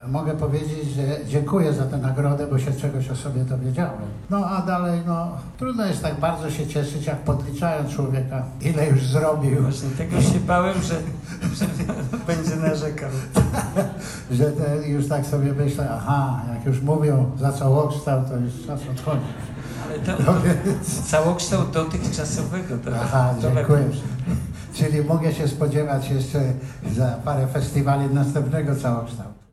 Gala finałowa Kozi Film Festiwal 2019. W Lubuskim Centrum Winiarstwa w Zaborze rozdano doroczne Klapsy - nagrody dla filmowych twórców.